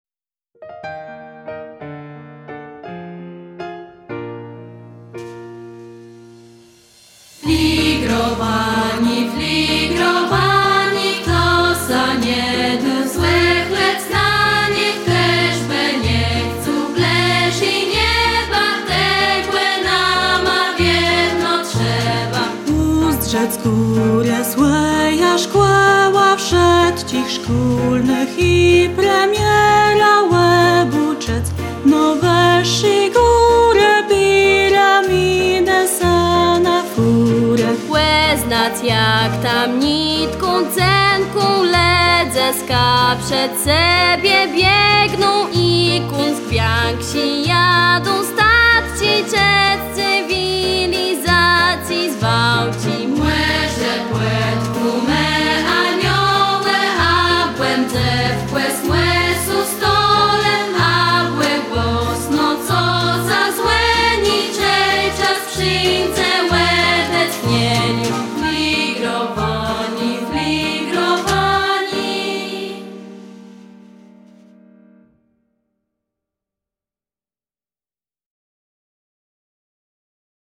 Nagranie wykonania utworu tytuł